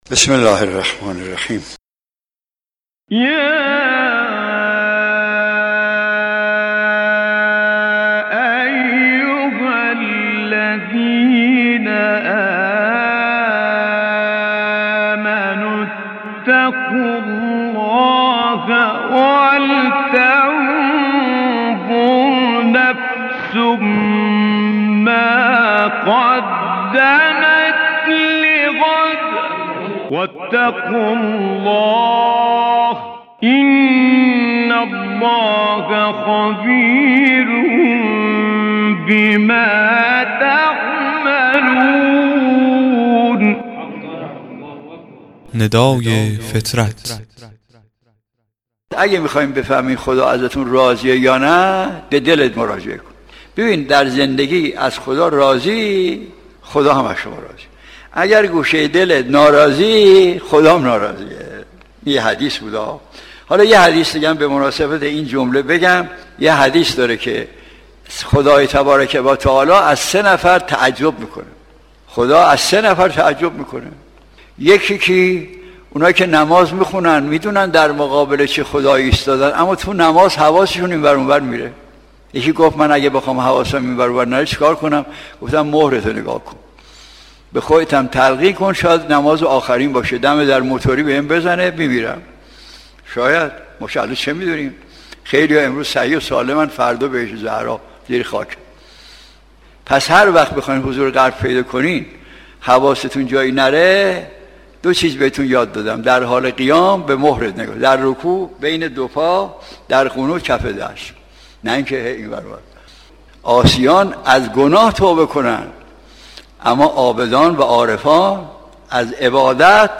قطعه کوتاه صوتی از آیت الله مجتهدی (ره) که روایتی زیبا را بیان می کند.